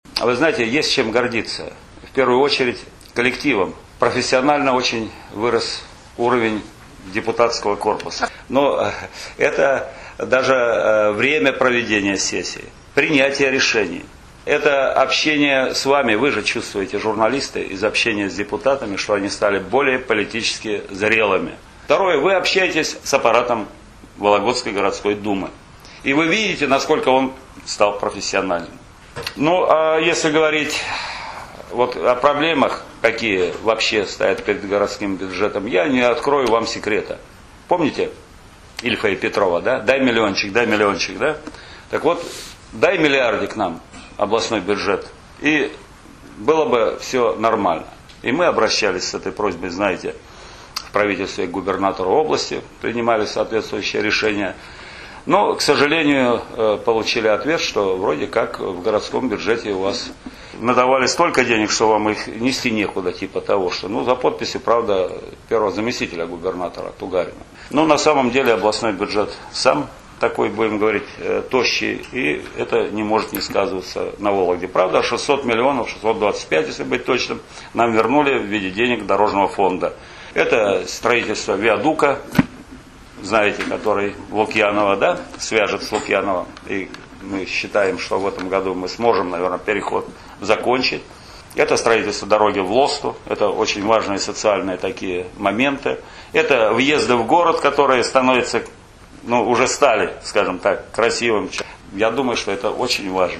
Председатель Вологодской городской Думы, Игорь Степанов ответил на вопросы журналистов. Пресс-конференцию по итогам работы городского парламента за полгода провели перед тем, как депутаты уйдут на летние «каникулы», сообщает ИА «СеверИнформ — Новости Вологды». Одним из первых был вопрос об основных достижениях депутатов за последнее полугодие, а также о проблемах.
Игорь Степанов рассказывает о работе Гордумы за полгода